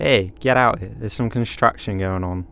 Voice Lines
Update Voice Overs for Amplification & Normalisation
Hey get out of here theres some.wav